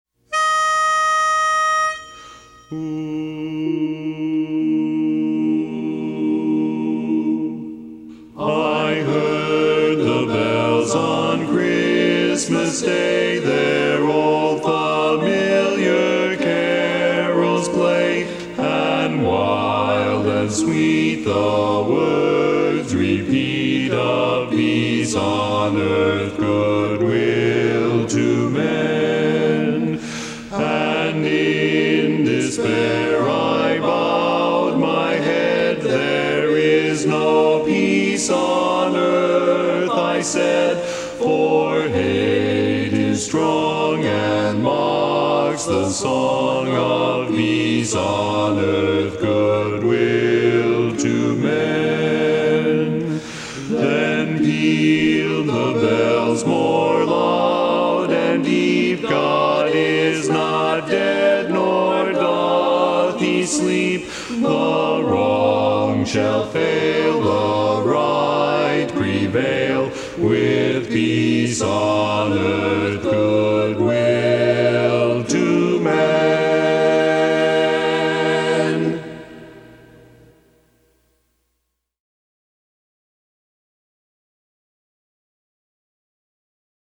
Barbershop